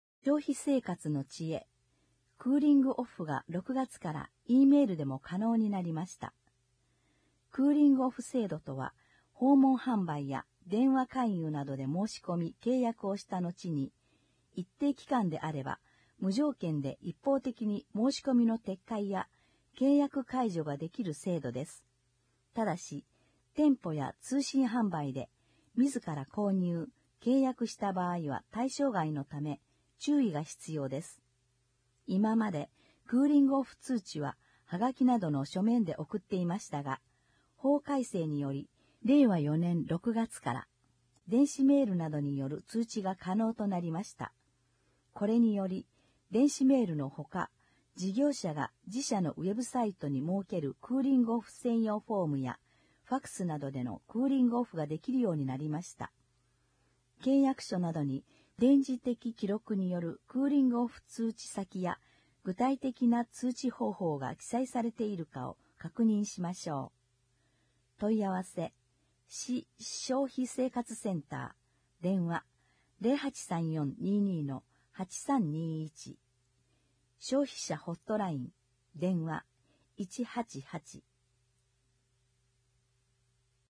音訳広報